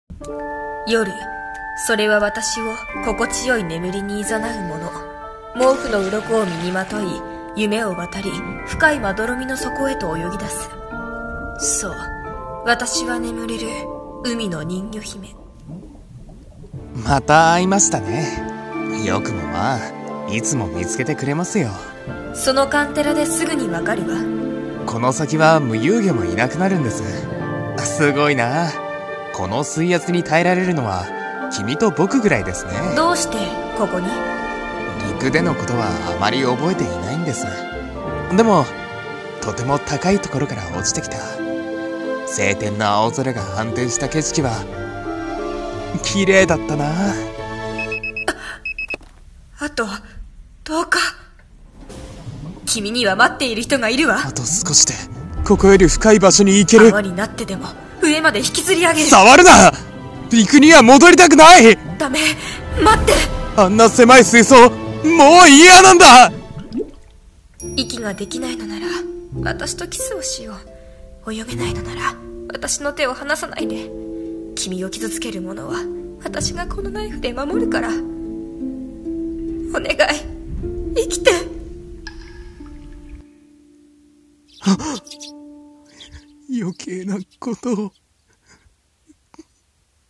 CM風声劇「睡深8400mより」